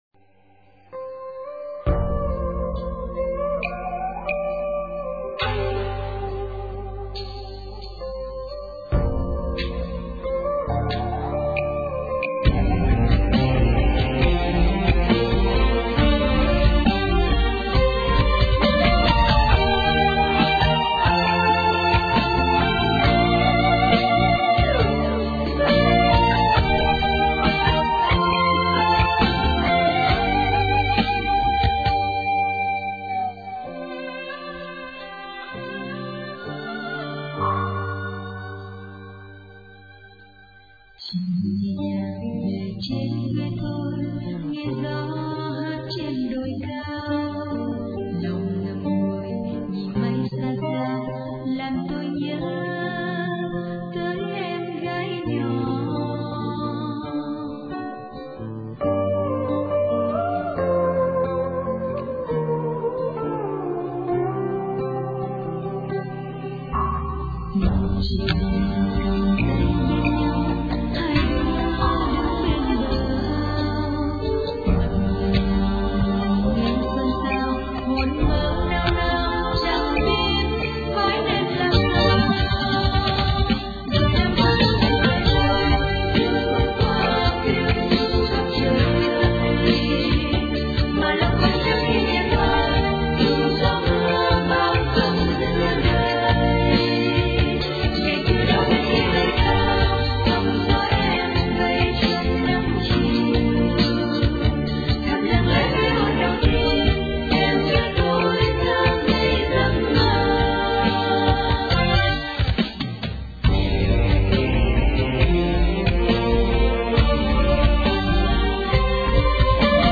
* Thể loại: Nhạc Việt